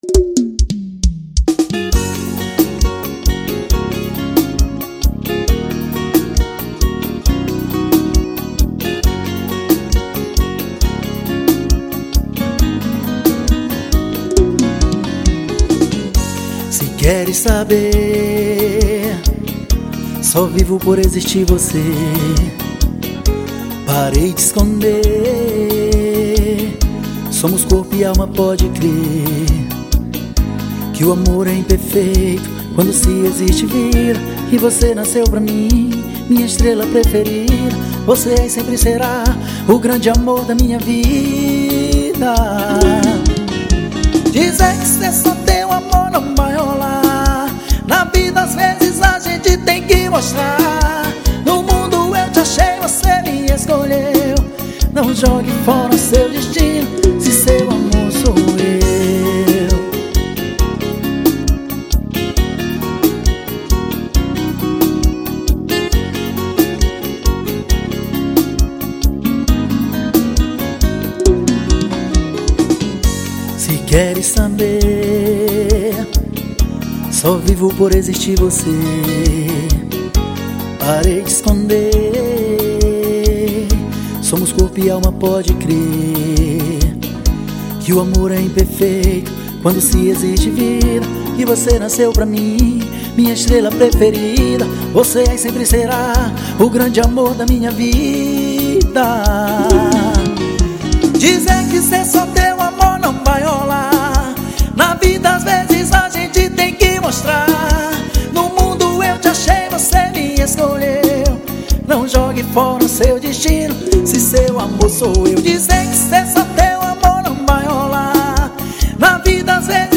Romântico.